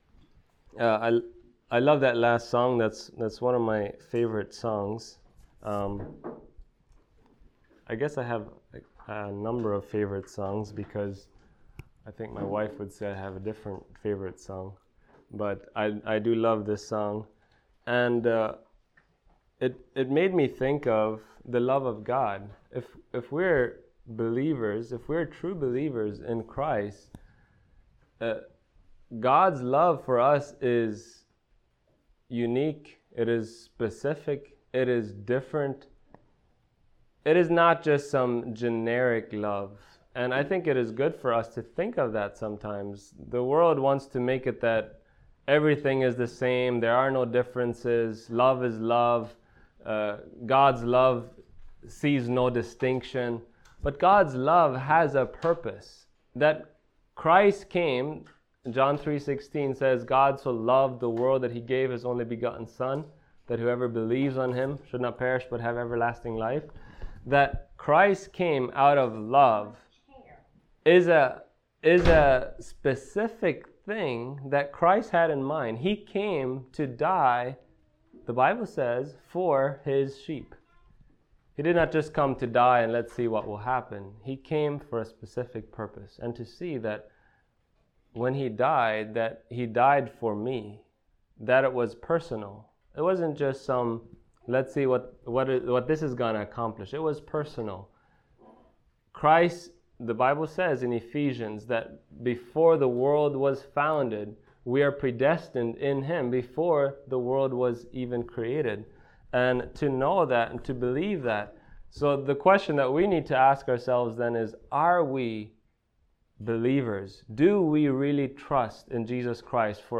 Passage: John 4:1-38 Service Type: Sunday Evening